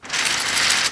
Song: sfx_mVenetian-blind-up